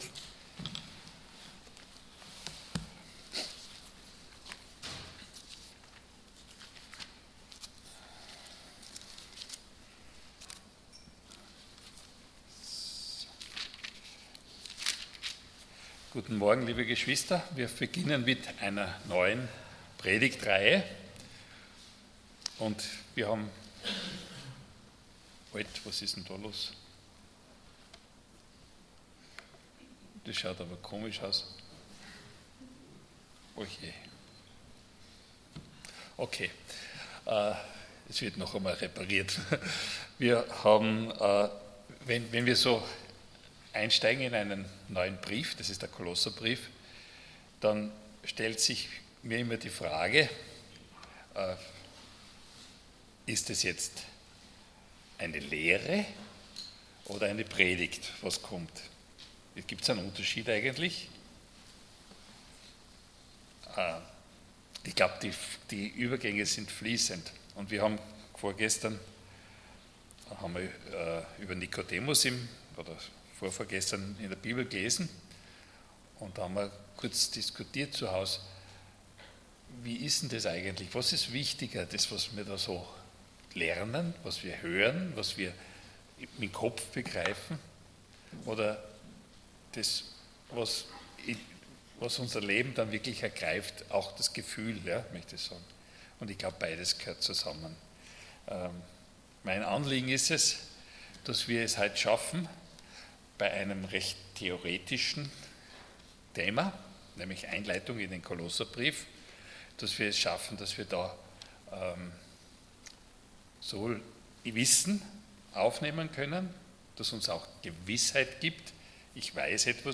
11.01.2026 Einleitung Kolosser-Brief Prediger